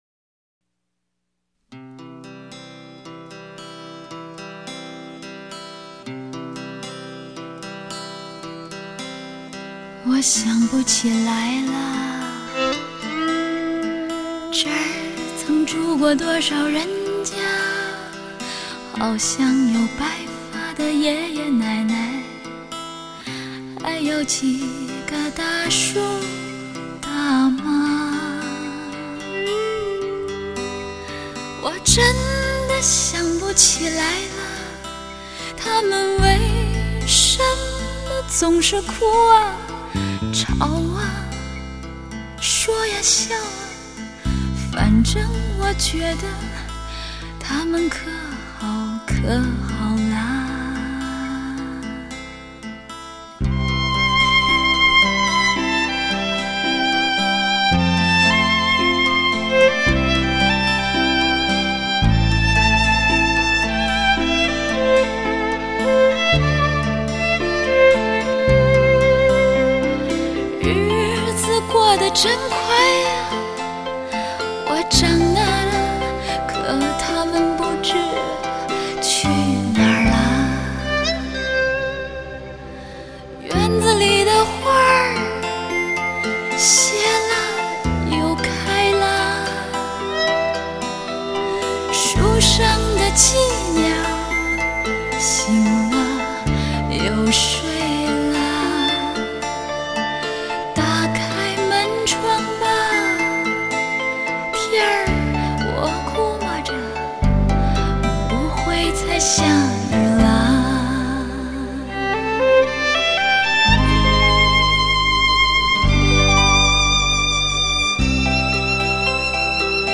她充满律动感，极富情调，色彩浓郁，大气而不失细腻，高贵而不失诚恳，就象一杯清晨的热牛奶，带给人一种暖洋洋的幸福感。